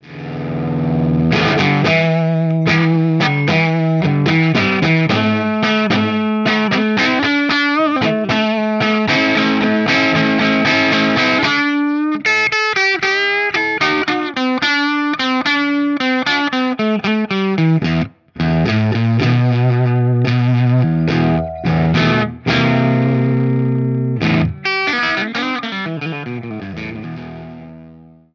Totally unstructured, free form clips!
With the JTM I used my TTA Trinity cab, the Marshall with Greenbacks, and the Mesa Recto with V-30s.
I set up 2 mics this time – a Shure SM57 and an Audix i5. The i5 has a more pronounced top end and bigger bottom and compliments the 57’s midrange thing really well.
E_JTM4_Input1Hi_PRSMira_HumbuckerBridge_Greens.mp3